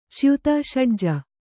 ♪ cyuta ṣaḍja